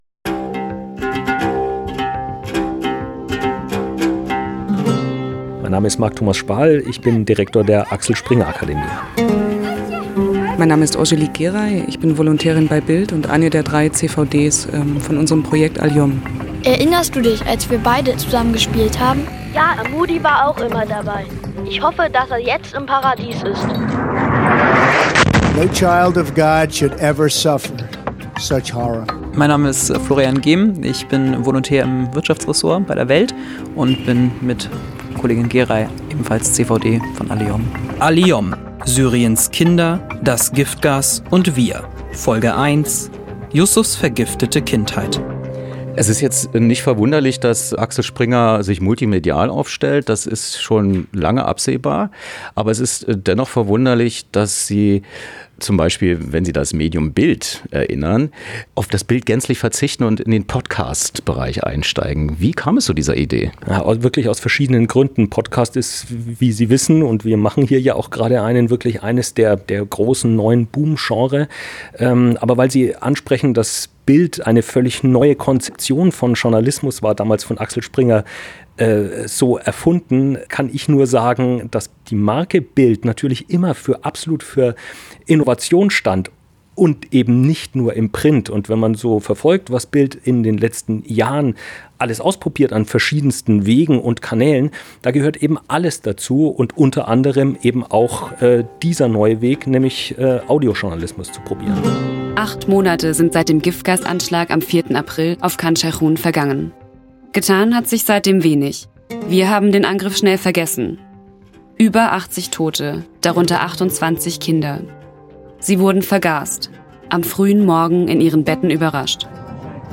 Collage über den PodCast ALYOM – Syriens Kinder, das Giftgas & Wir“ der Axel-Springer-Akademie
Axel-Springer-Hochhaus, Berlin